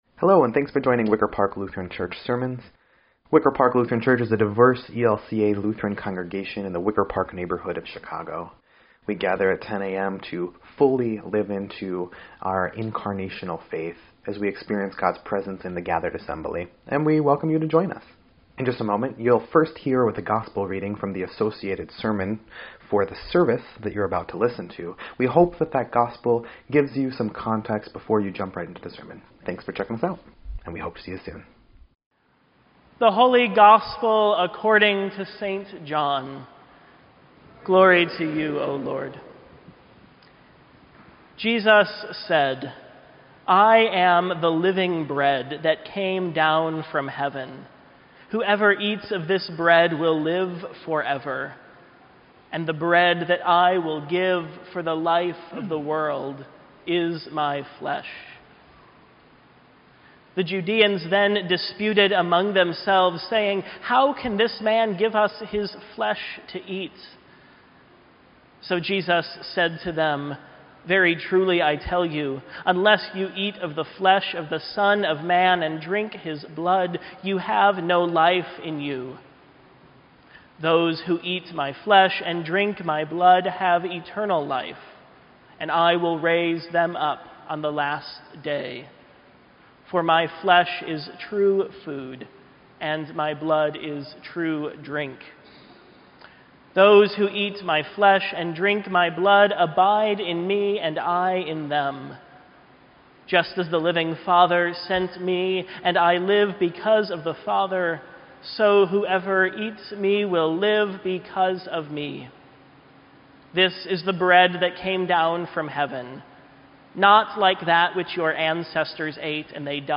EDIT_Sermon_8_19_18.mp3